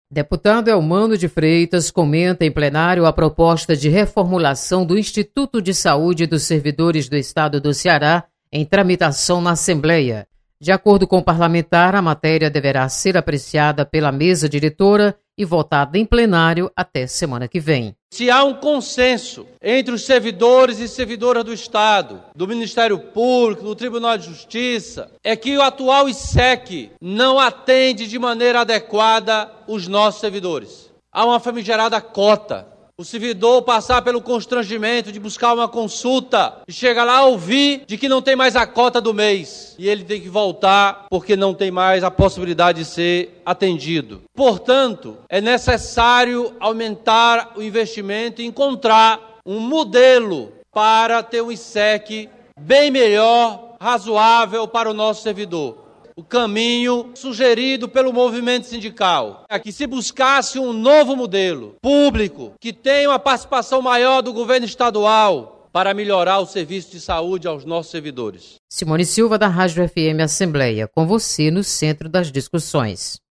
Deputado Elmano de Freitas  defende reformulação do Issec. Repórter